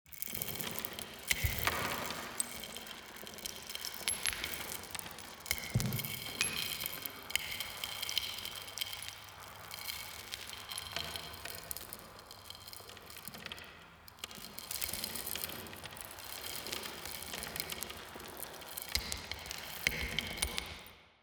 BlockFallBasic.wav